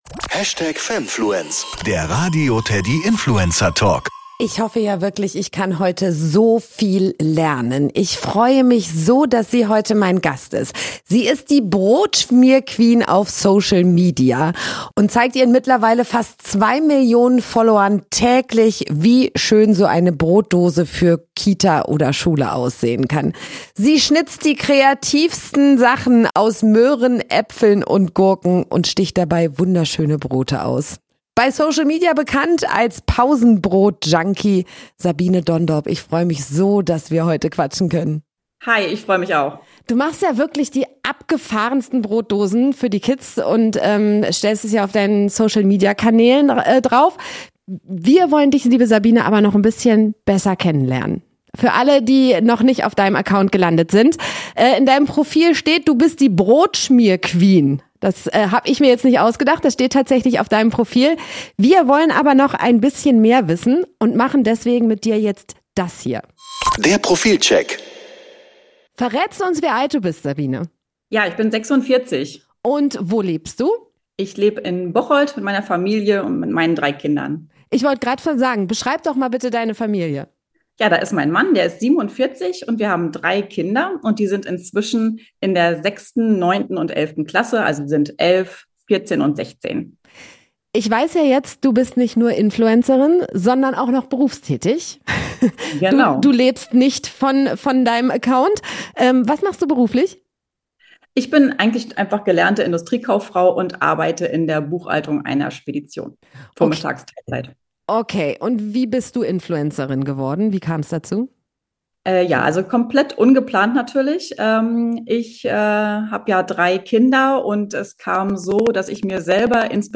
Liebe geht durch den Kindermagen ~ Der Radio TEDDY Influencer-Talk Podcast